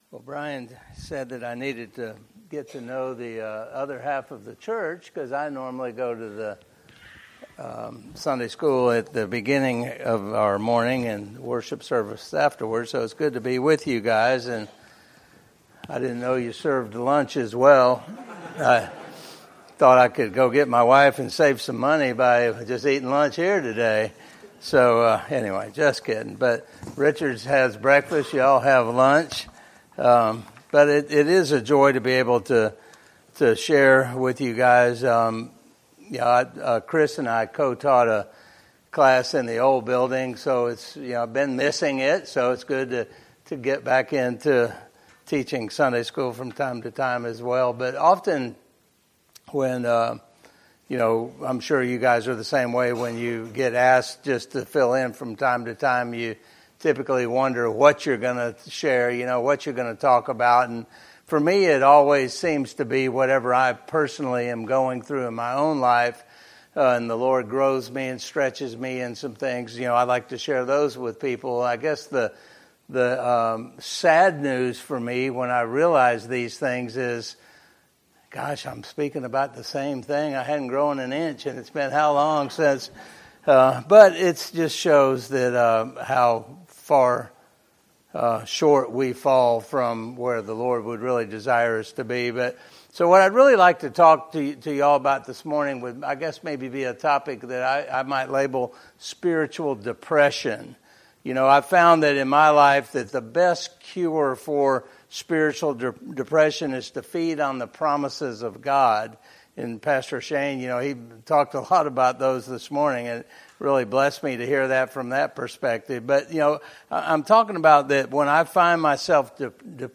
Adult Bible Study